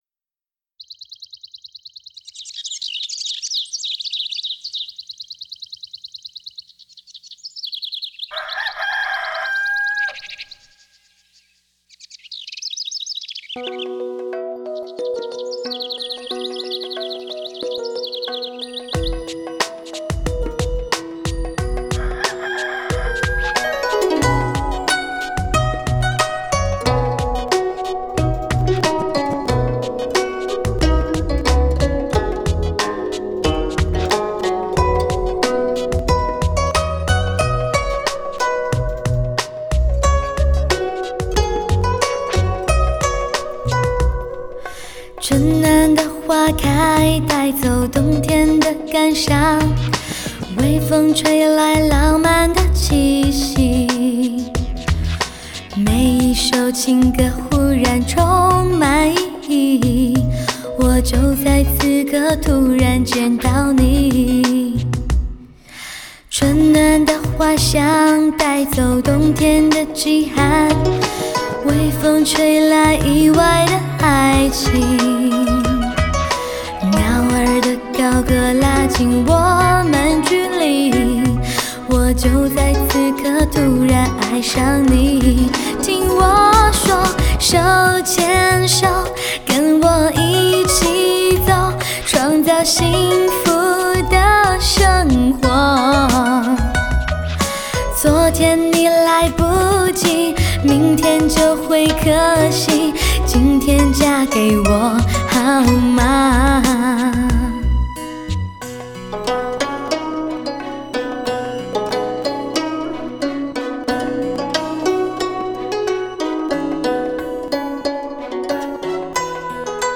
新21世纪型全方位女声
一股清新氛围引领至最极限的欢快，如同走进仙境般的世界。
天衣无缝的演唱，嘹亮直接、变幻莫测的唱腔一展无遗，出众的技巧被Show得一气呵成。